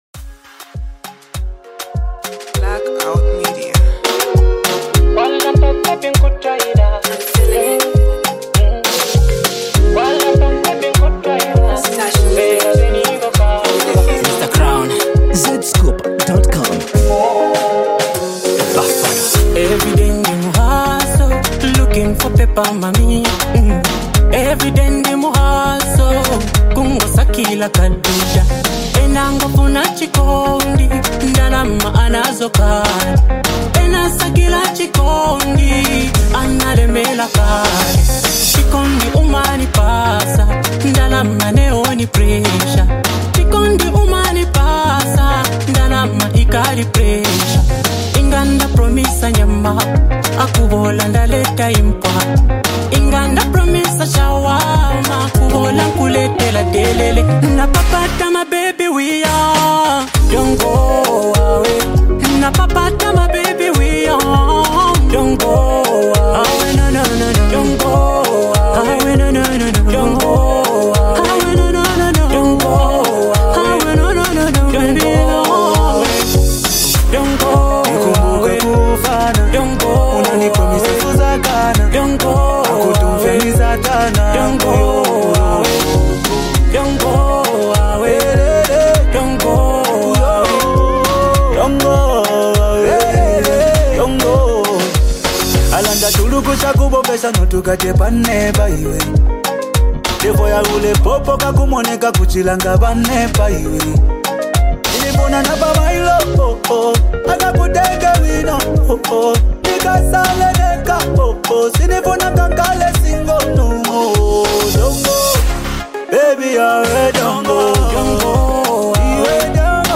nice well orchestrated Zambian tune
This is a club anathematic song which will go a long way.